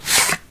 cardTakeOutPackage1.ogg